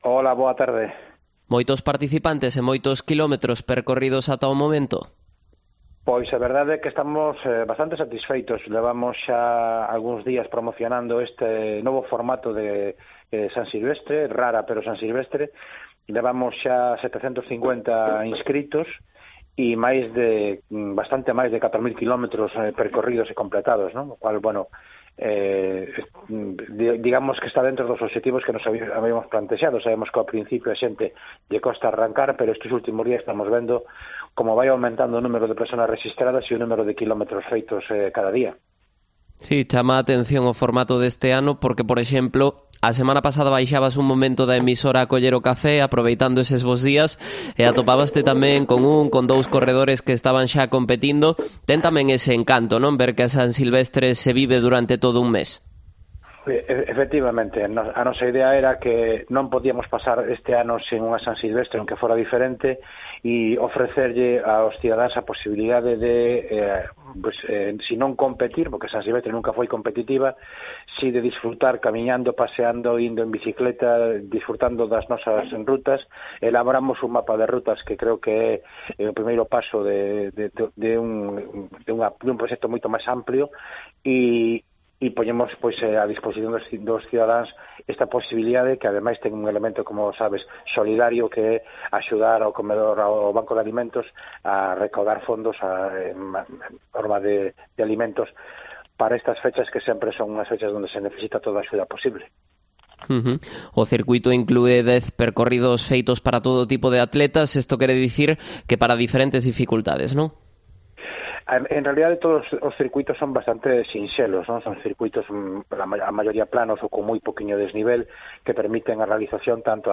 Entrevista a Tino Fernández, concejal de Deportes en Pontevedra